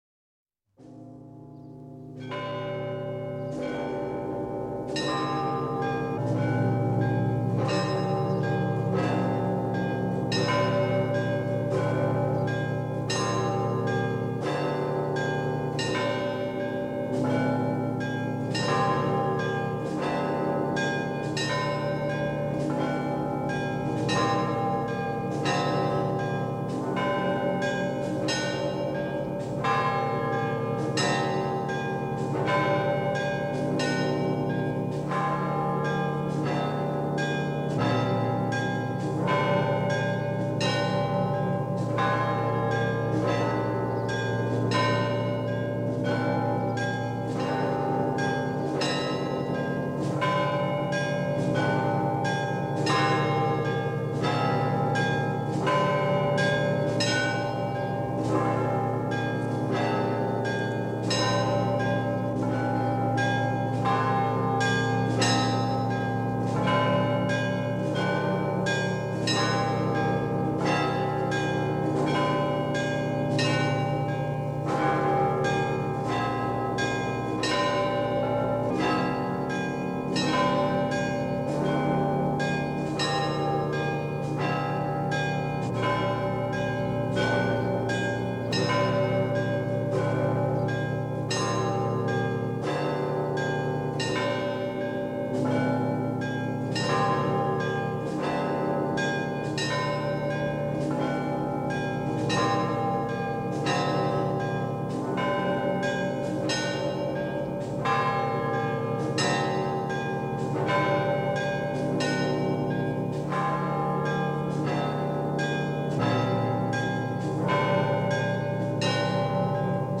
Index of /lib/fonoteka/etnic/rossia/kolokola/rostov-2
11_Ioakimovskij_Perezvon.mp3